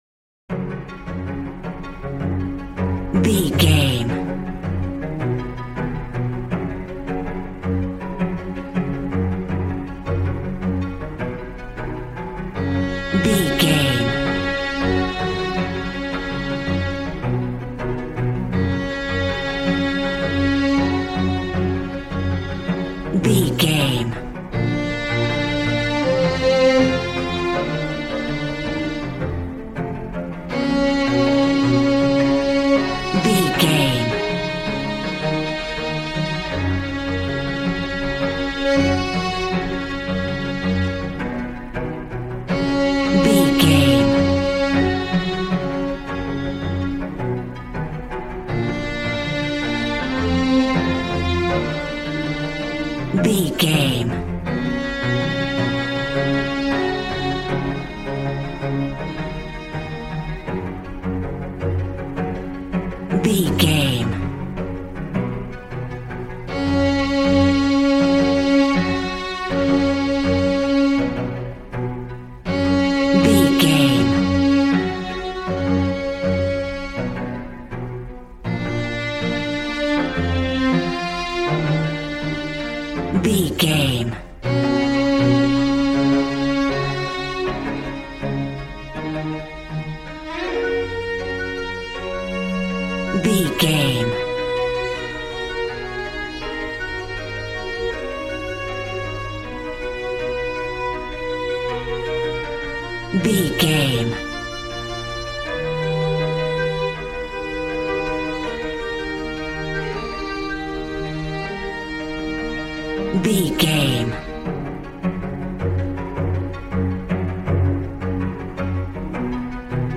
Modern film strings for romantic love themes.
Regal and romantic, a classy piece of classical music.
Ionian/Major
G♭
regal
cello
violin
brass